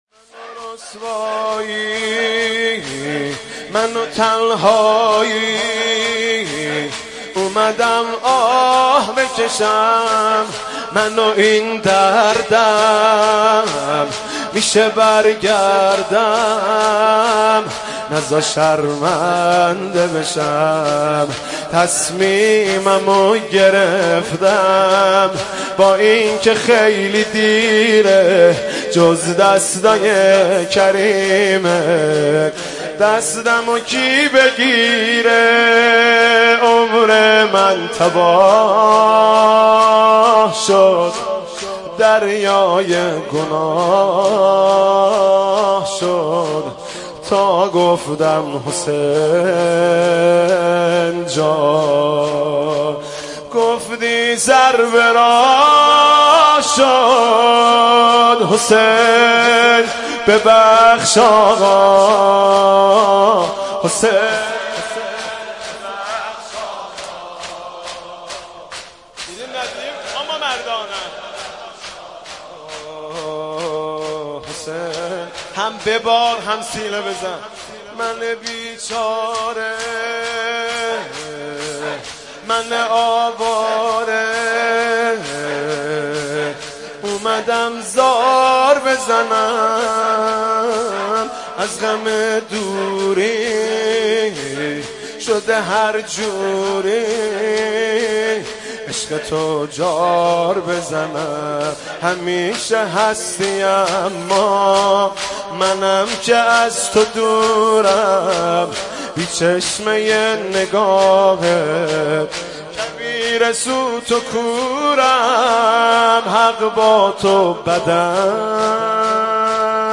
نوحه جديد
مداحی صوتی
شور